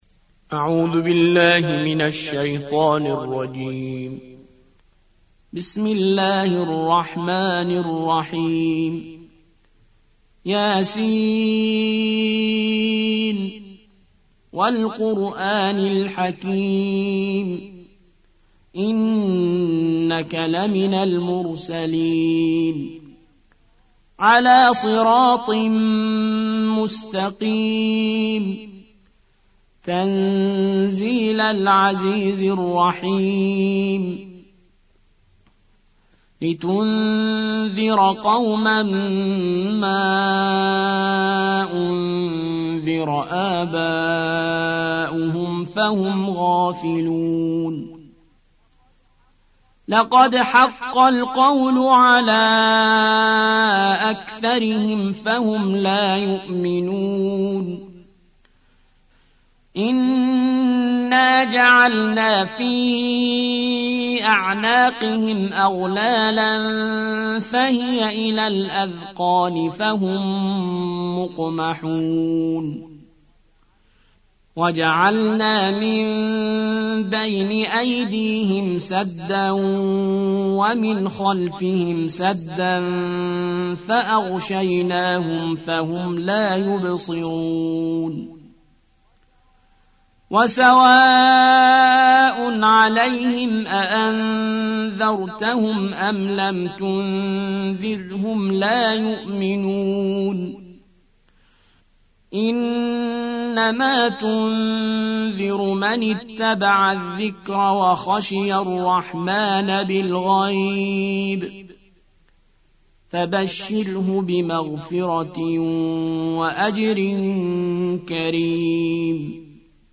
36. سورة يس / القارئ